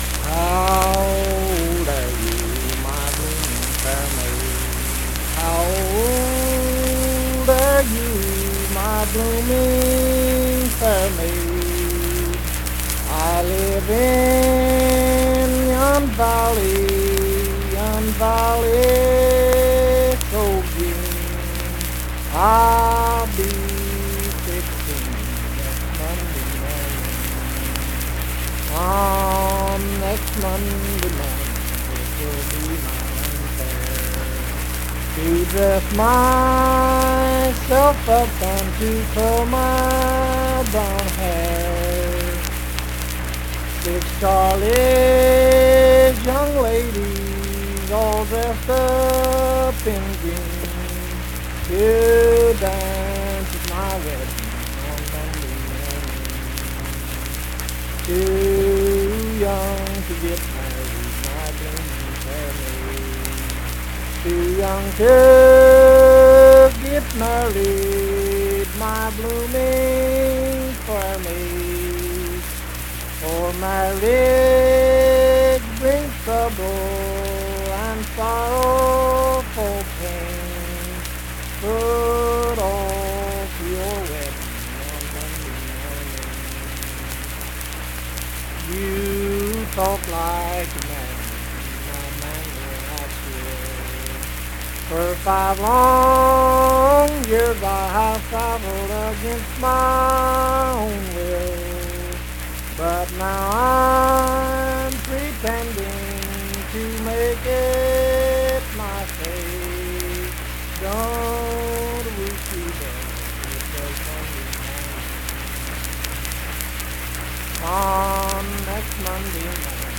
Unaccompanied vocal music
Voice (sung)
Saint Marys (W. Va.), Pleasants County (W. Va.)